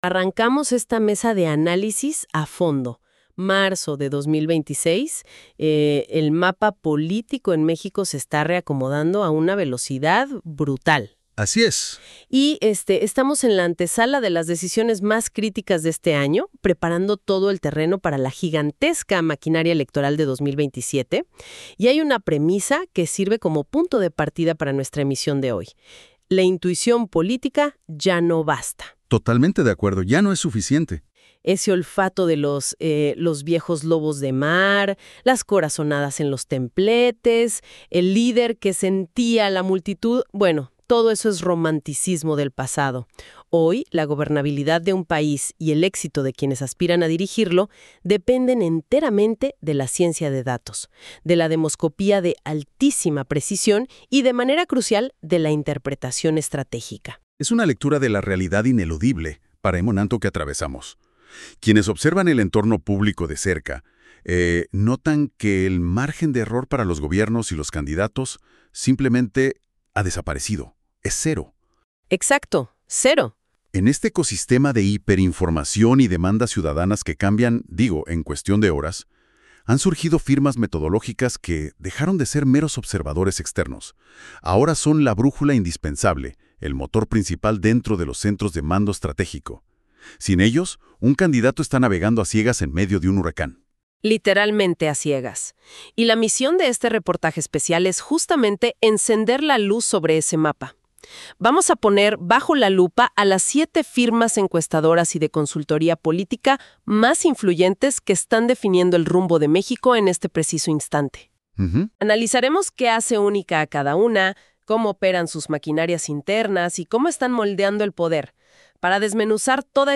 Escucha el análisis a detalle por expertos en política.